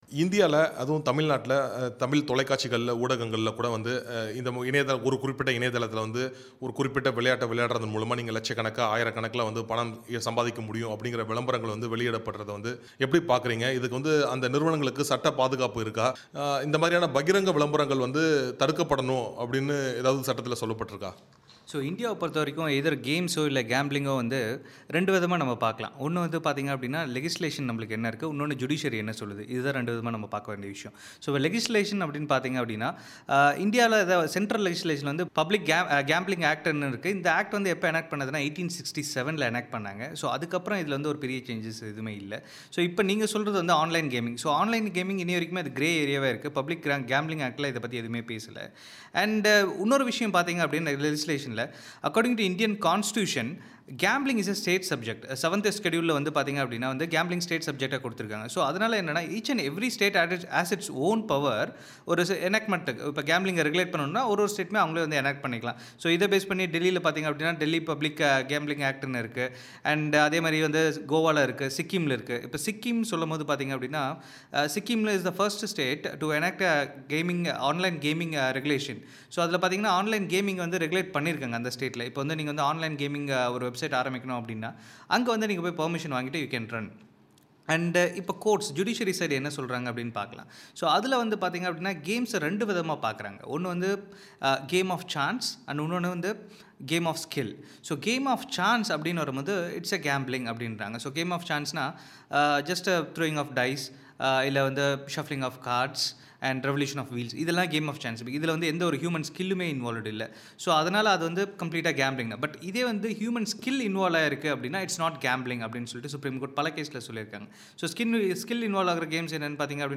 கலந்துரையாடலின் ஒலி வடிவத்தை இங்கு கேட்கலாம்